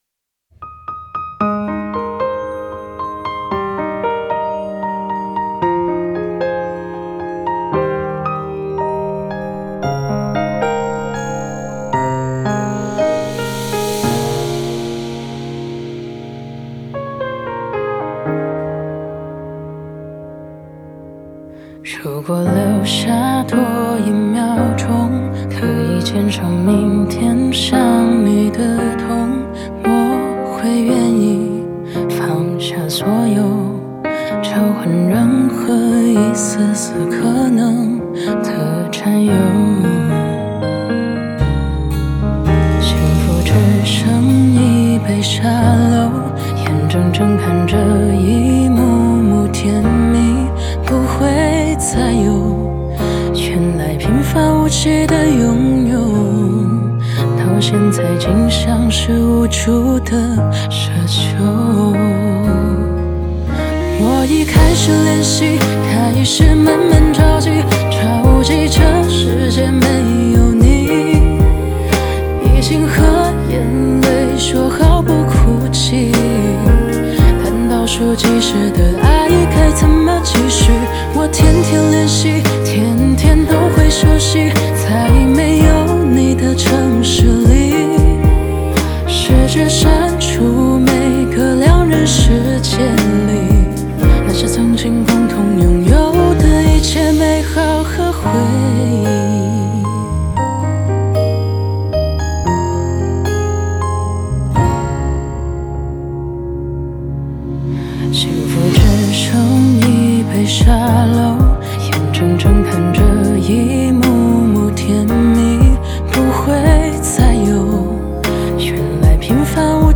Ps：在线试听为压缩音质节选，体验无损音质请下载完整版
女声版